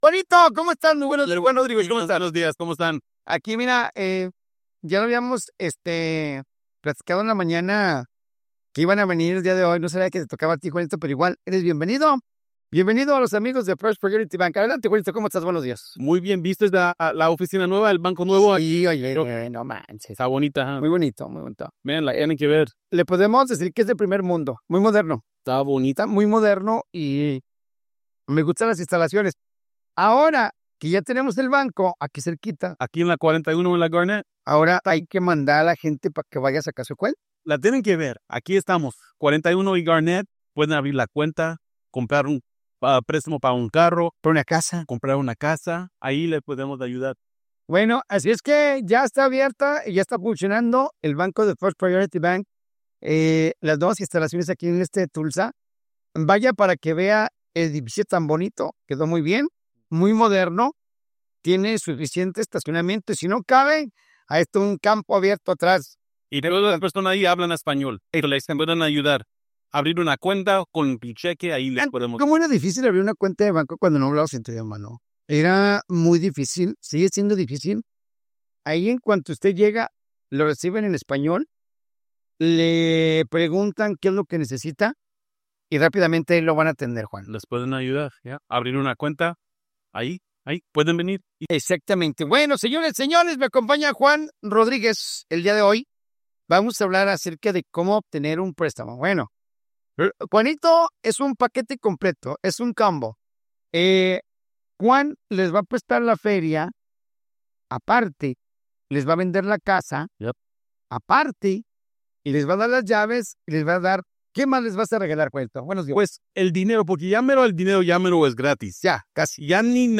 Para conocer requisitos, beneficios y opciones concretas, escucha la asesoría completa en el podcast disponible ya, donde especialistas del banco explican paso a paso cómo acceder a estos productos y cómo empezar el proceso.